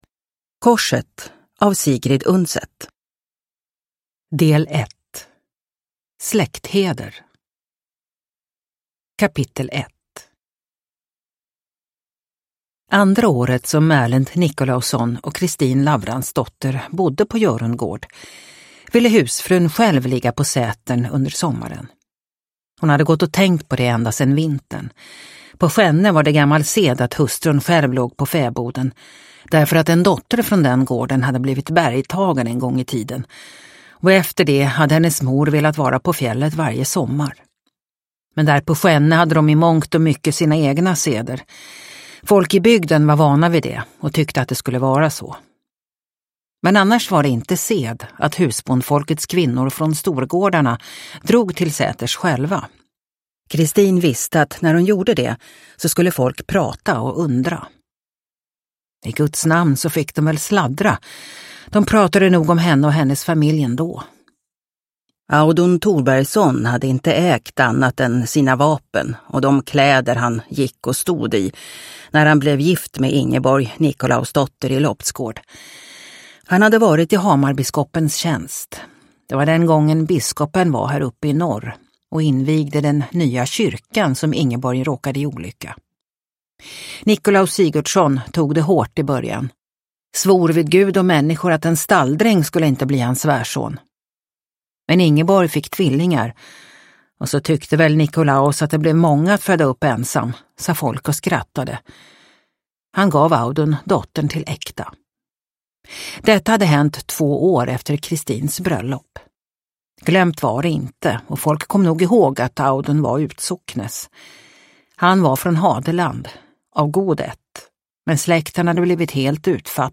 Korset – Ljudbok – Laddas ner